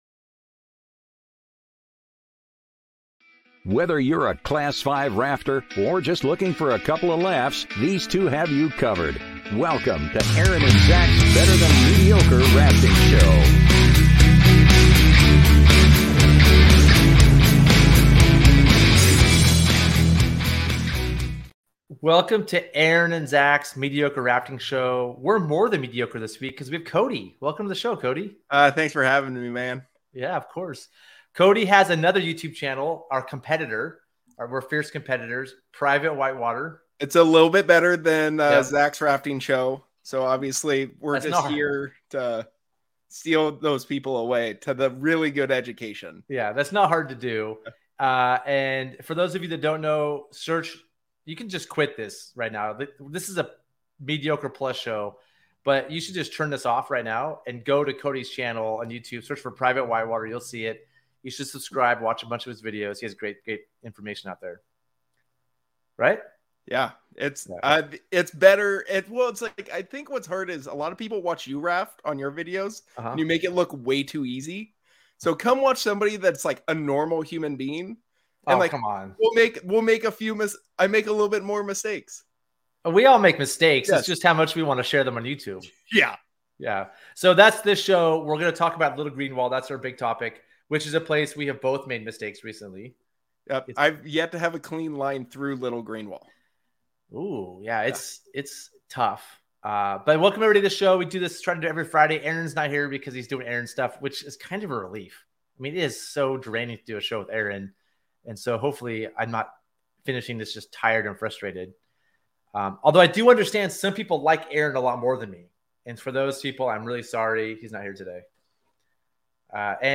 Gear Garage Live Show Little Green Wall